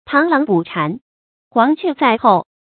táng láng bǔ chán，huáng què zài hòu
螳螂捕蝉，黄雀在后发音